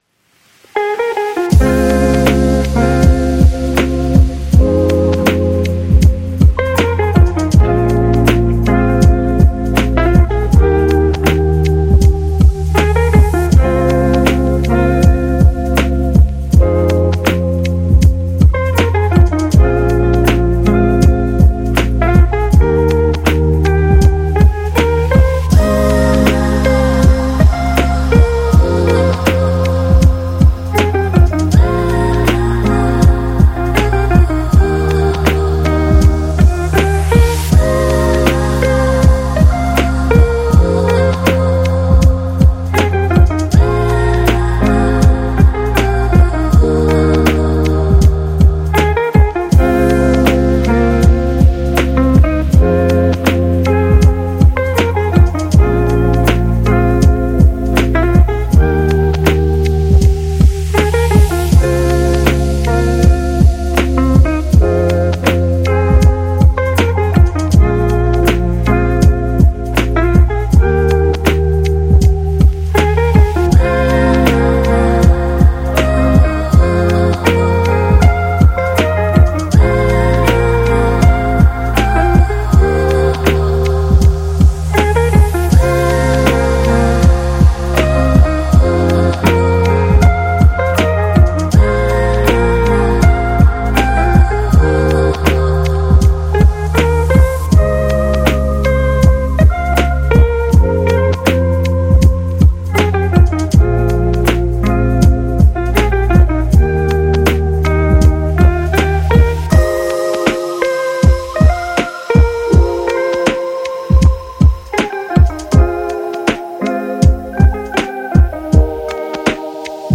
The Lofi